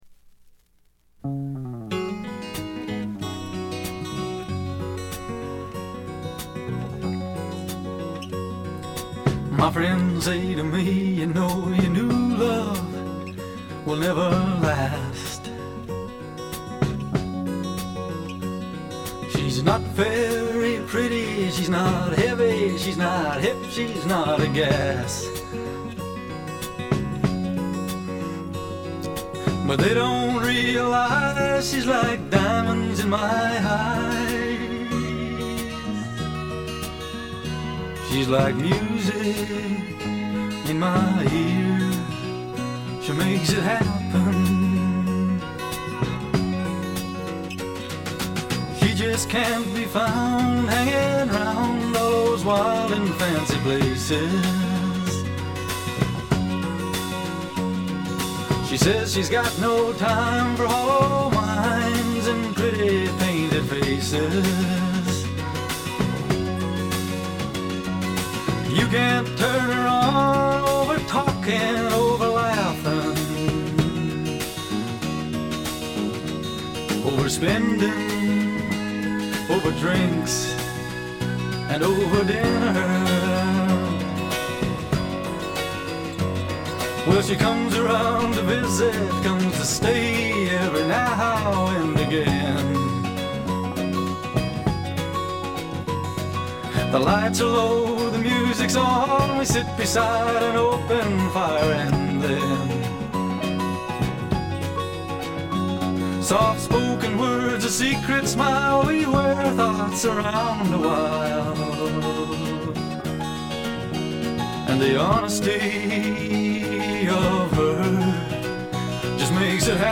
カナディアンらしくカントリー臭とか土臭さはあまり感じません。
試聴曲は現品からの取り込み音源です。